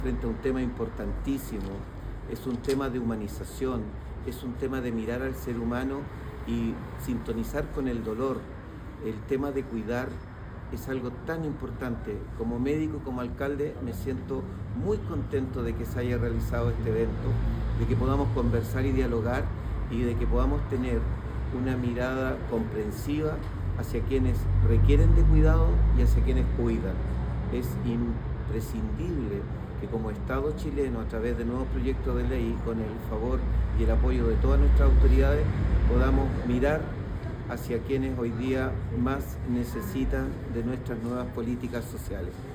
El alcalde de Quillota, Luis Mella, asistió a la charla impartida por la ministra de la Mujer y Equidad de Género, donde se abordaron los alcances del Sistema Nacional de Cuidados.
El alcalde quillotano hizo énfasis en que el Estado debe comprometerse en ayudar en el cuidado de las personas con dependencia:
Alcalde-Luis-Mella-2.mp3